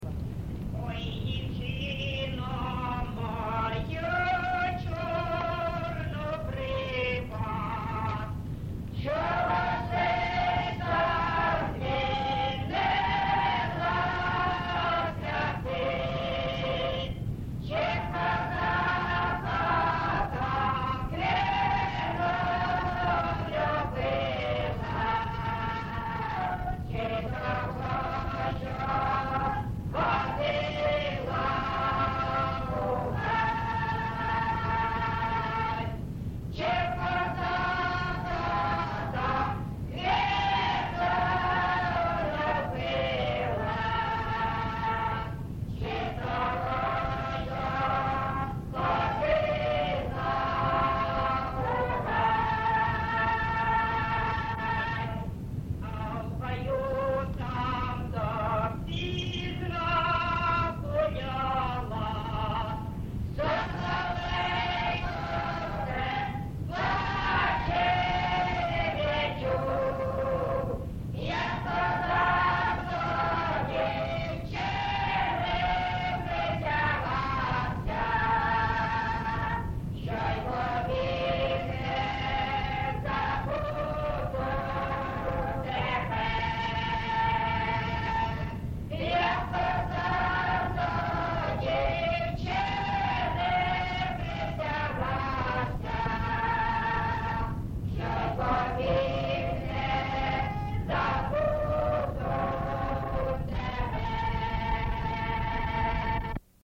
ЖанрПісні з особистого та родинного життя, Романси
Місце записус. Семенівка, Краматорський район, Донецька обл., Україна, Слобожанщина